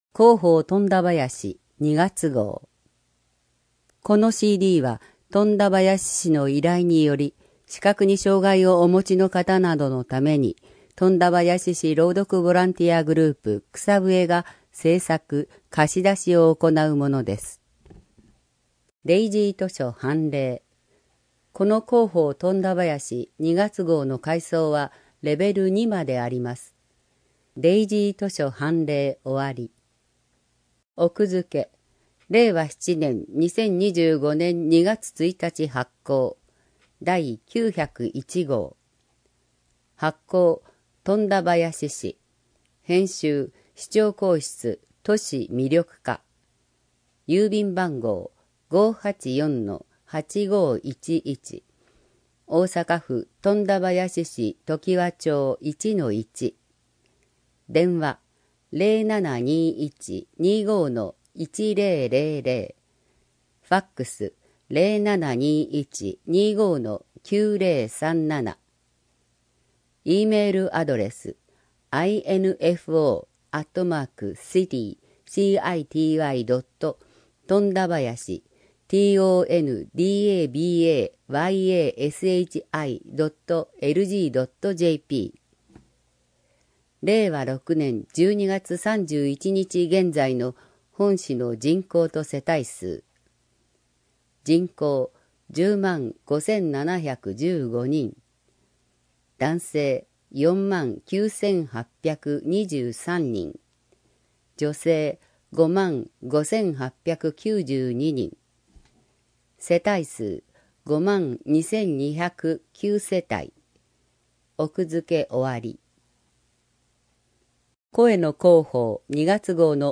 この音声は、本市の依頼により富田林市朗読ボランティアグループ「くさぶえ」が視覚に障がいをお持ちの人などのために製作しているものです（図やイラストなど一部の情報を除く）。